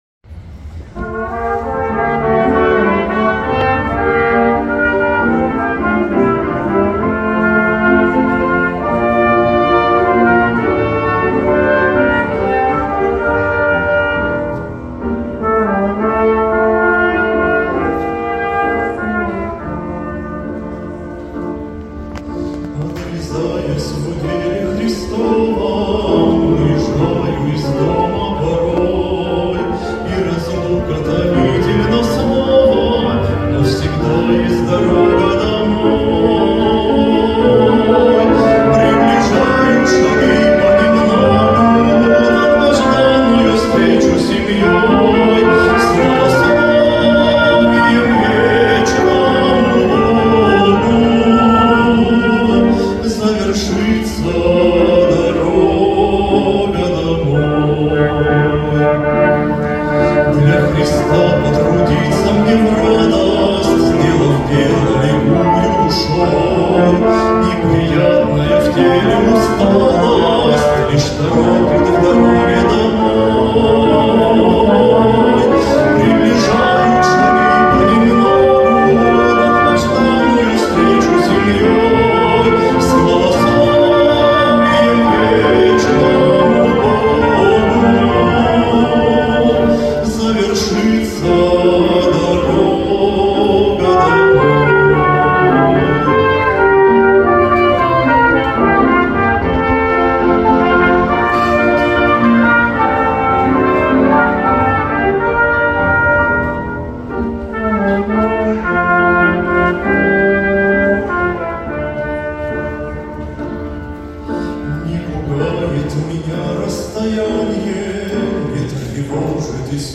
64 просмотра 54 прослушивания 2 скачивания BPM: 92